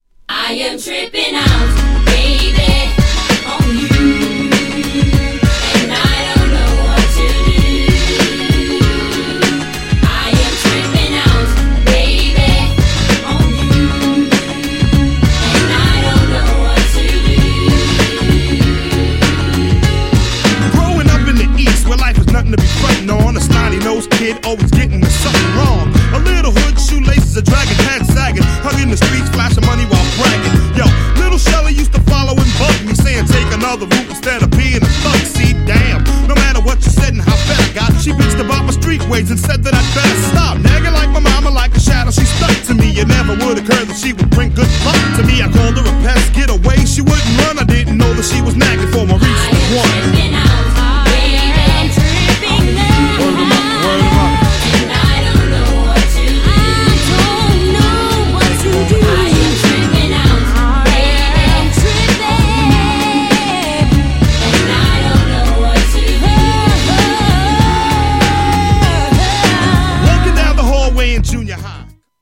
GENRE Hip Hop
BPM 106〜110BPM